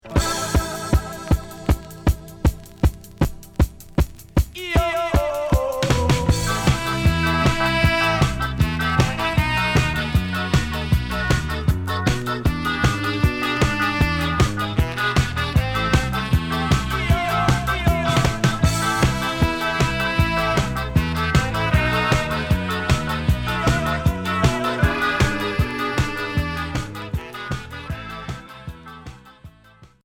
Ska Unique 45t retour à l'accueil